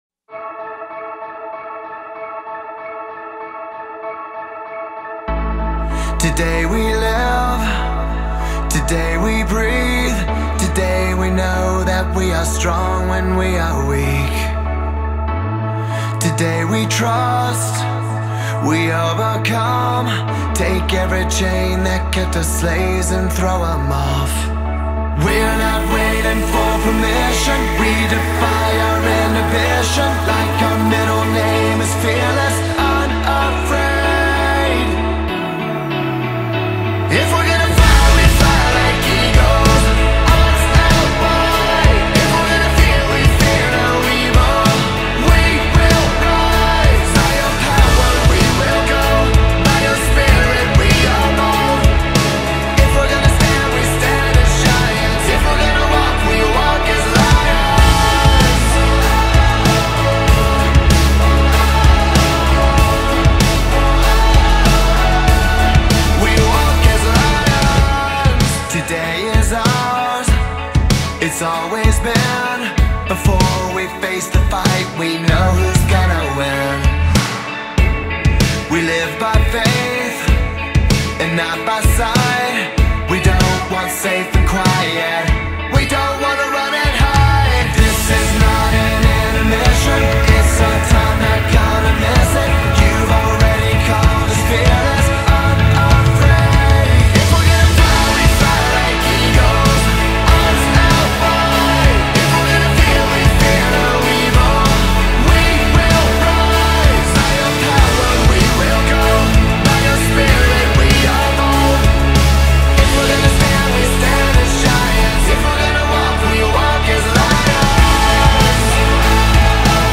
612 просмотров 221 прослушиваний 24 скачивания BPM: 96